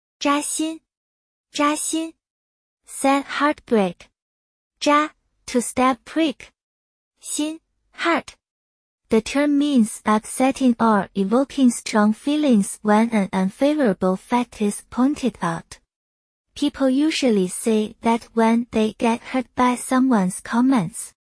zhā xīn